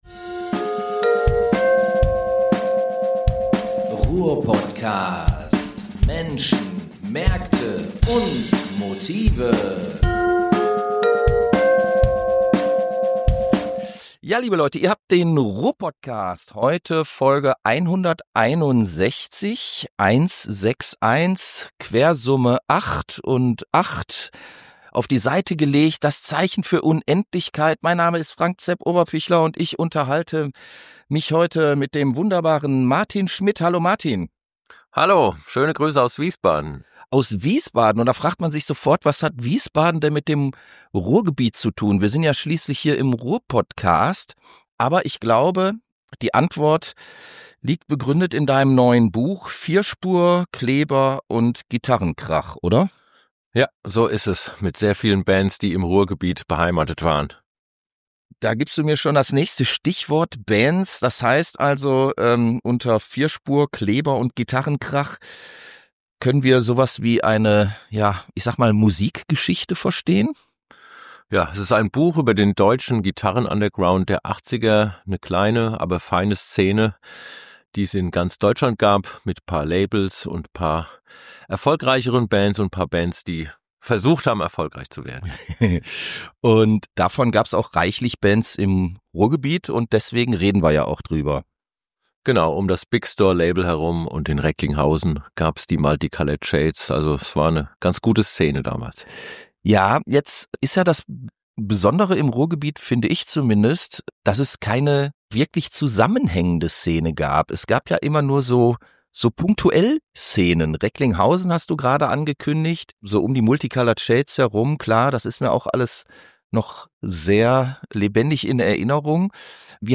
Ein Grund mehr, sich mit ihm über Bands und Labels der Ära zu unterhalten.